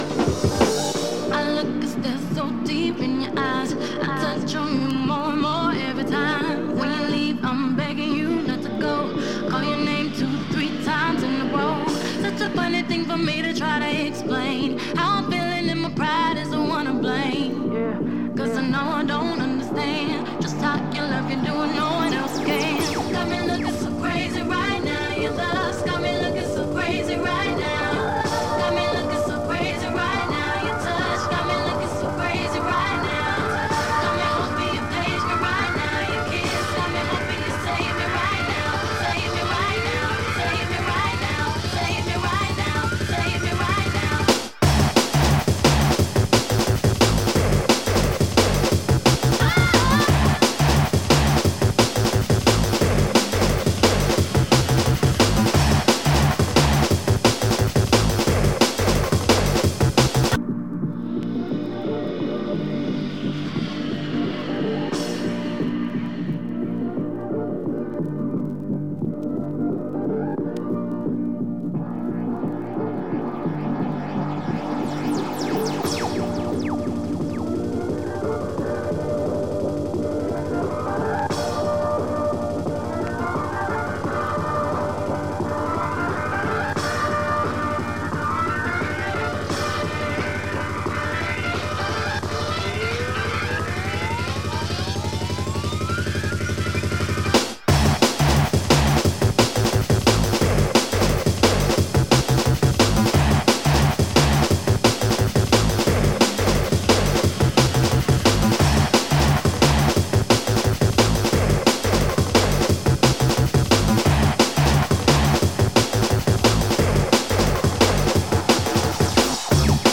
D&B Vocal Mix
D&B Instrumental
Drum N Bass